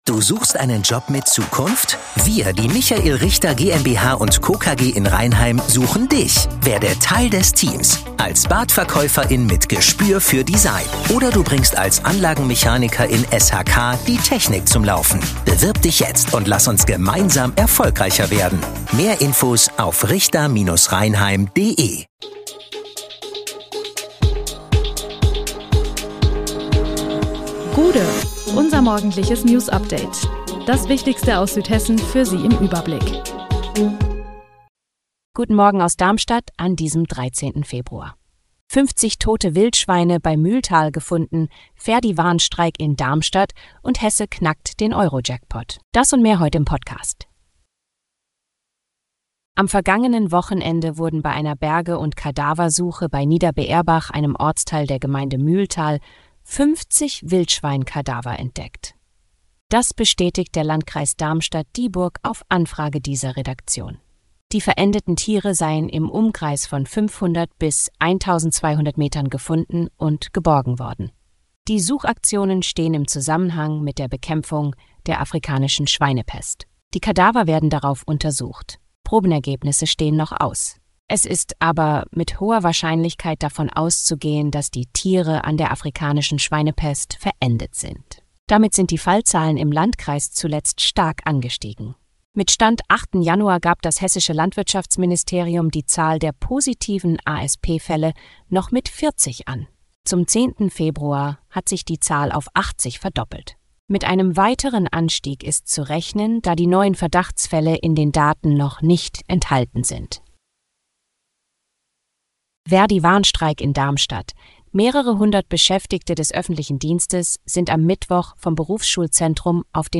Der Podcast am Morgen für die Region!
Nachrichten